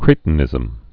(krētn-ĭzəm)